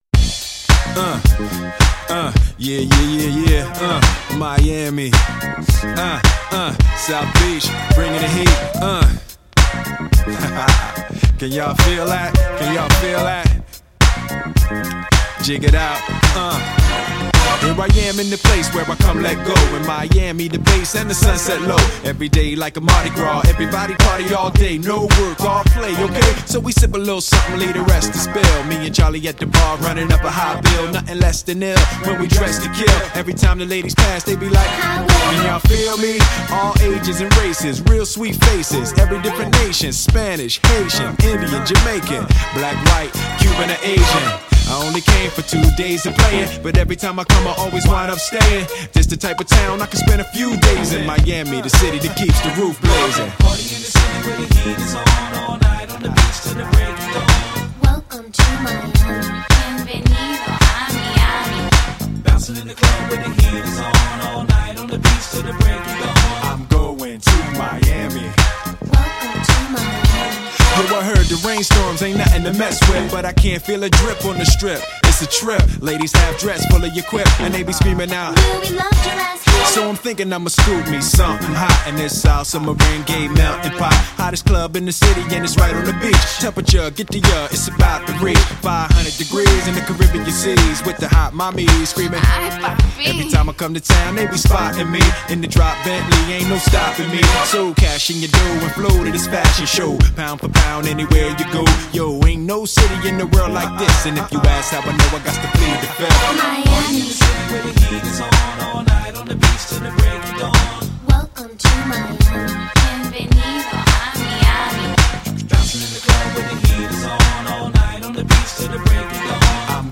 HipHop 2010er